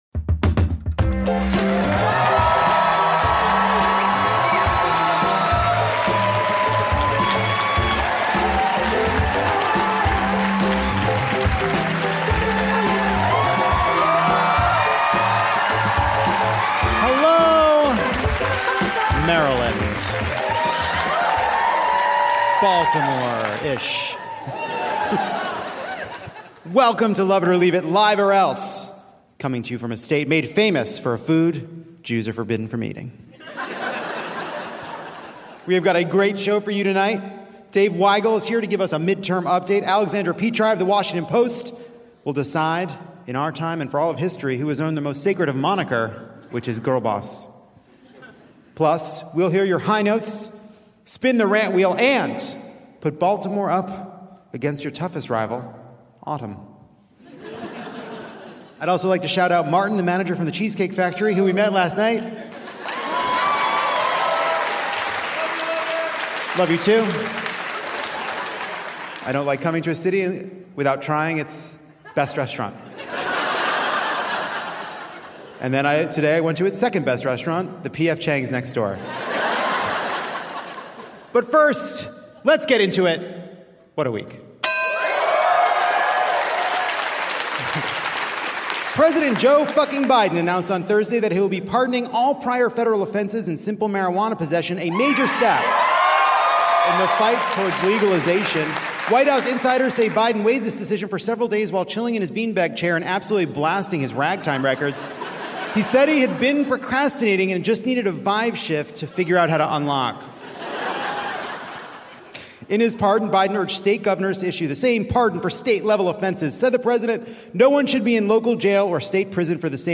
Old Bay v. Pumpkin Spice (Live from Baltimore!)
Lovett Or Leave It takes the stage at Goucher College and enjoys a little bit o’ Baltimore (okay, more specifically, Towson, Maryland). The Washington Post’s Alexandra Petri looks back at the incredible women who girl bossed, gaslit, and gobbled sailors whole throughout history. Reporter Dave Weigel leads us up the mountain that is the midterms.